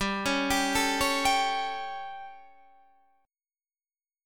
G Suspended 2nd Flat 5th